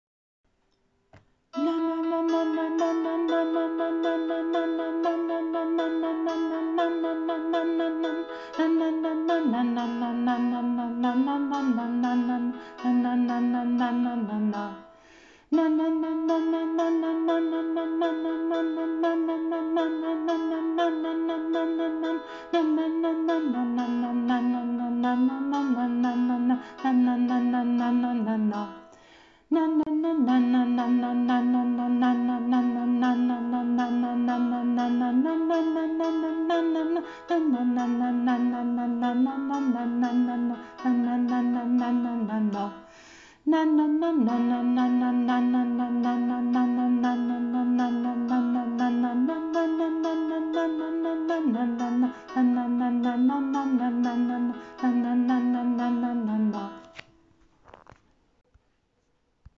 Na-na-Alt-langsamer.mp3